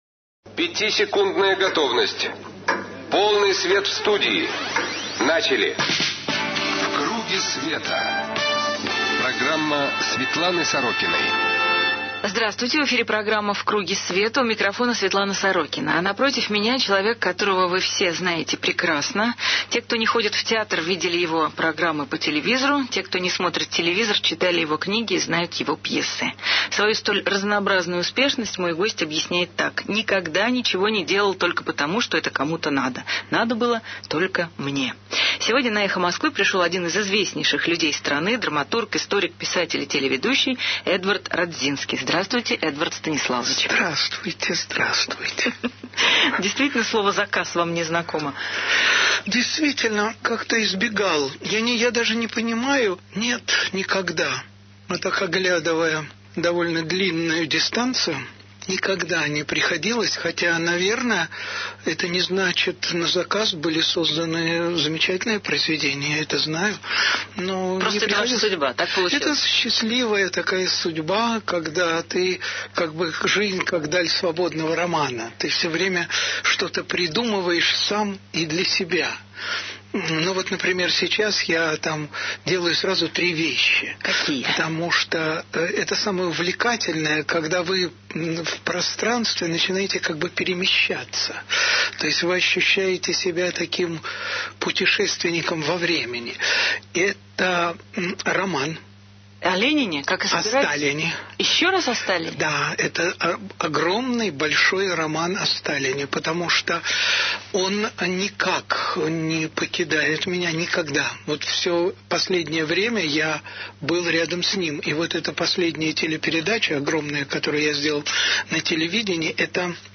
В круге света. Светлана Сорокина на радио "Эхо Москвы"
Светлана Сорокина: передачи, интервью, публикации